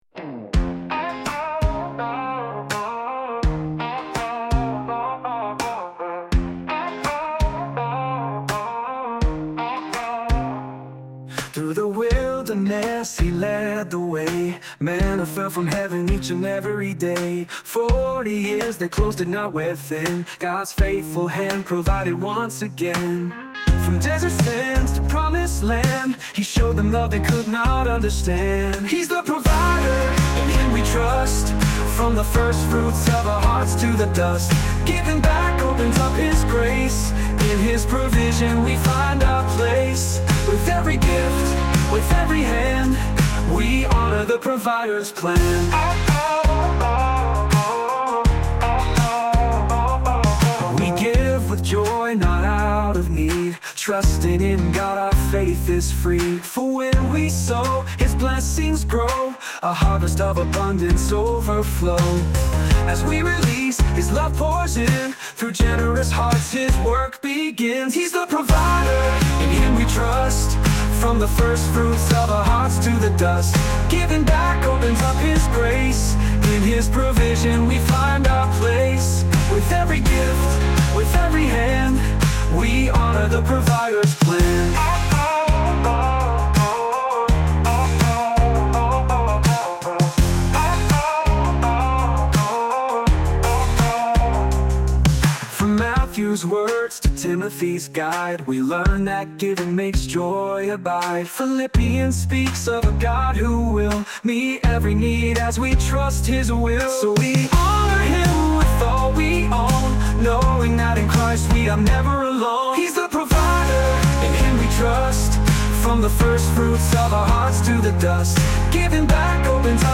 livingStone Sermons
Overview of Worship Service